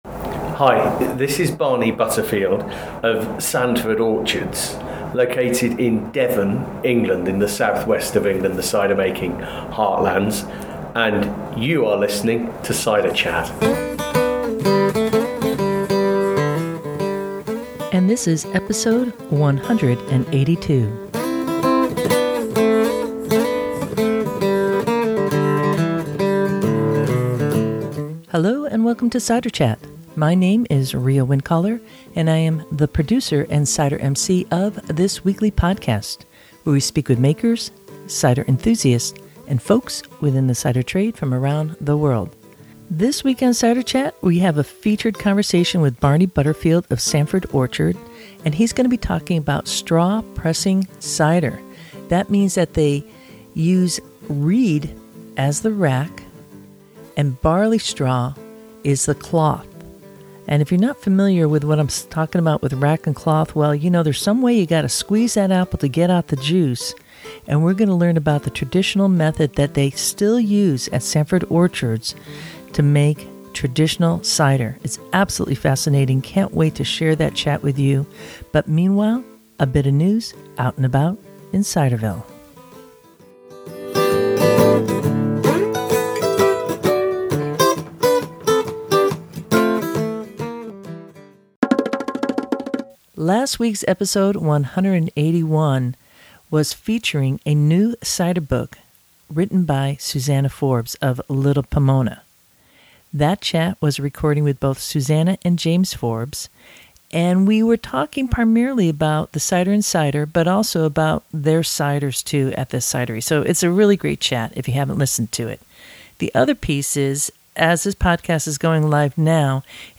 This chat was prerecorded at CiderCon2019.